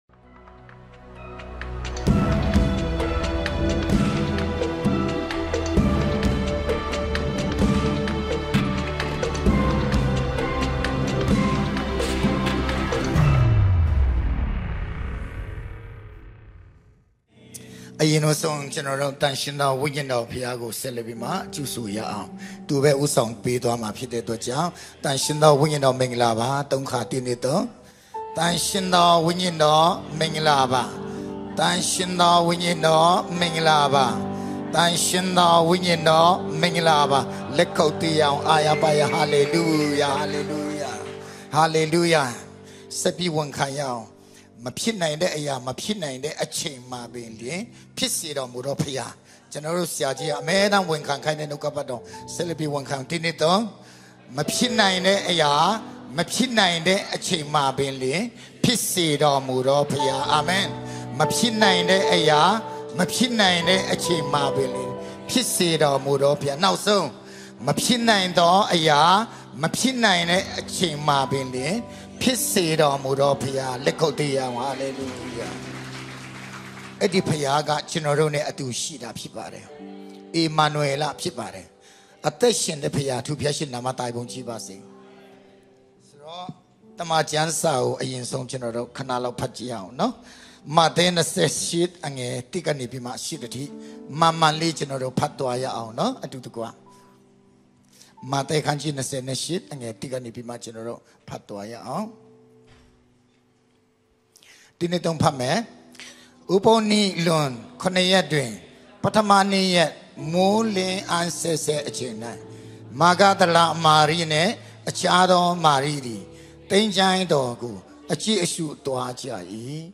Service-2